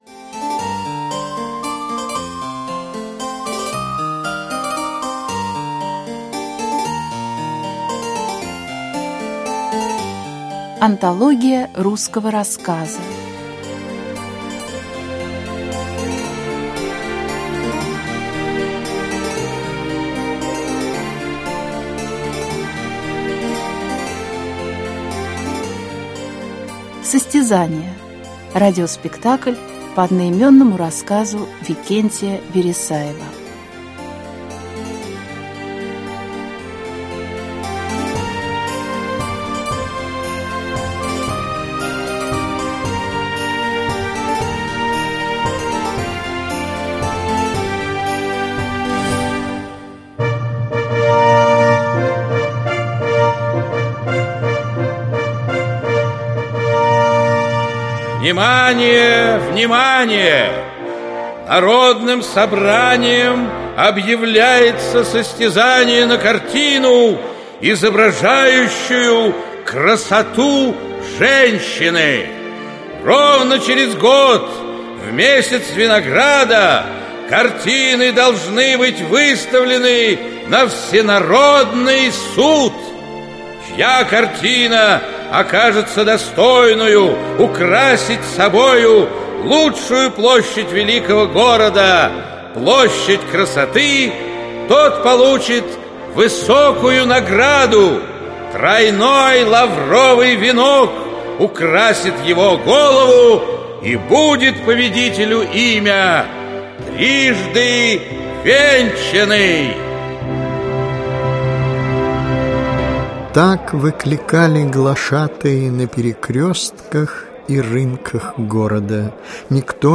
Аудиокнига Рассказы | Библиотека аудиокниг
Aудиокнига Рассказы Автор Викентий Вересаев Читает аудиокнигу Вячеслав Шалевич.